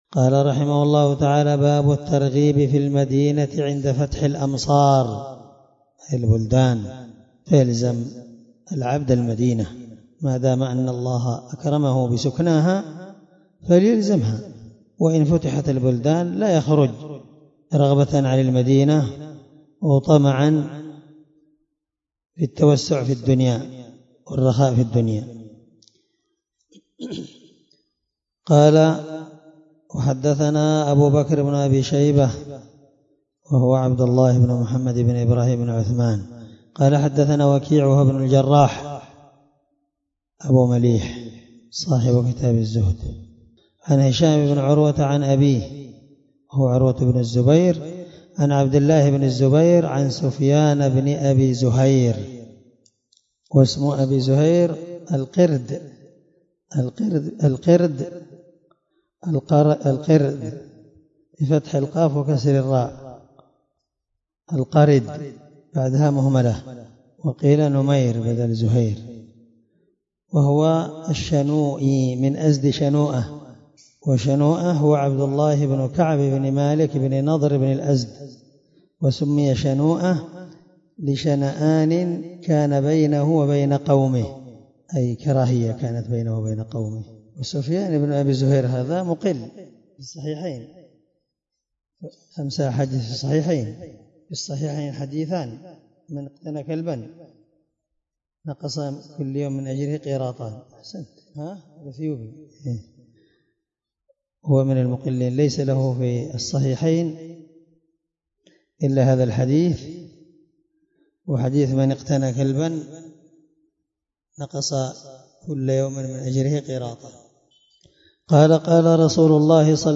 الدرس97من شرح كتاب الحج حديث رقم(1388) من صحيح مسلم